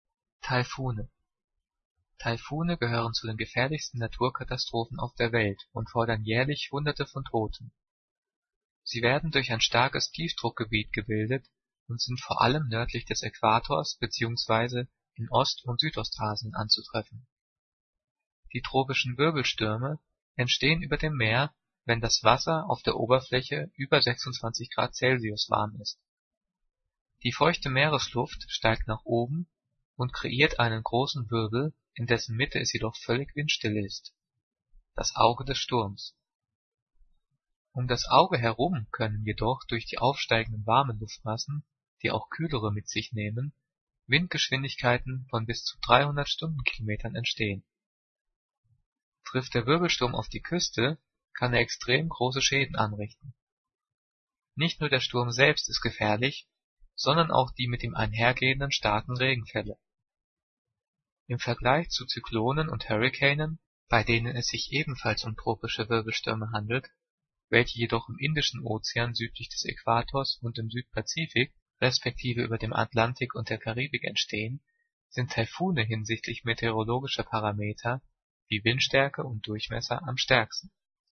Diktat: "Taifune" - 9./10. Klasse - Umlaute
Gelesen:
gelesen-taifune.mp3